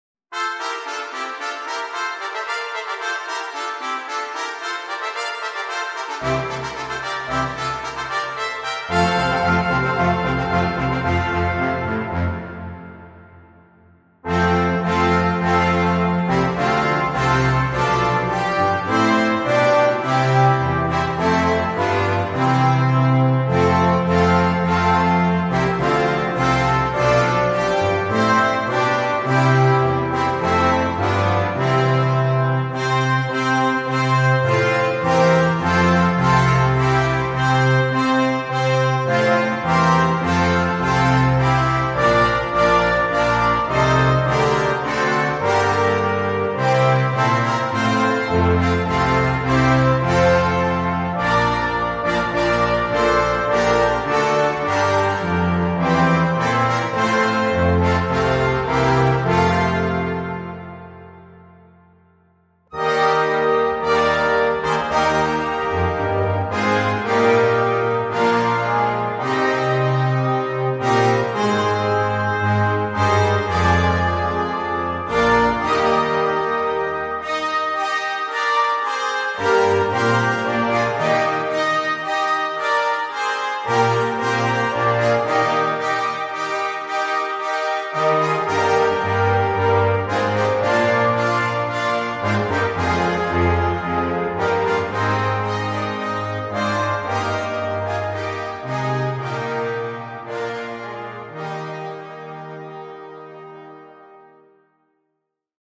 Besetzung Blasorchester